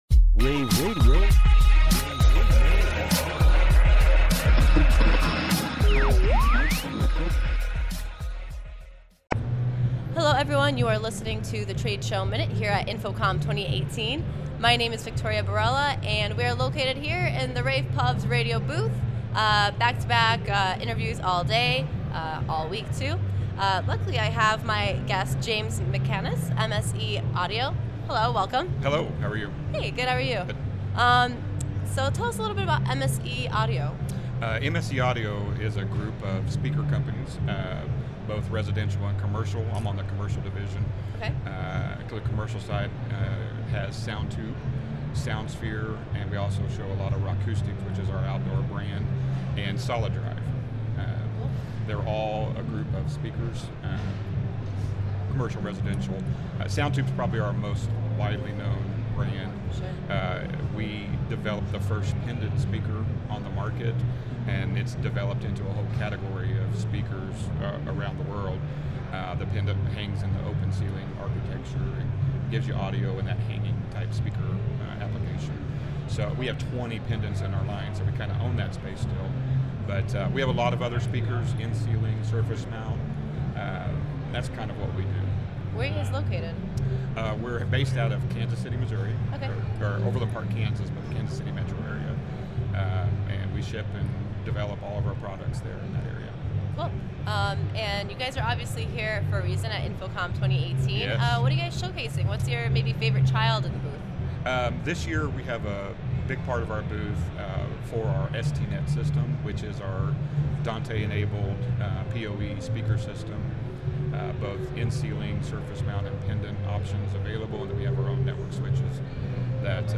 InfoComm Day2_showmin-226.mp3